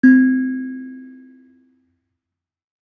kalimba1_circleskin-C3-ff.wav